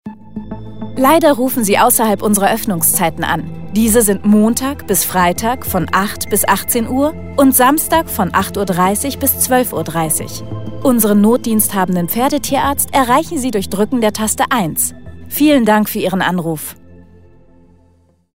Telefonansage Kleintierpraxis
AB Ansage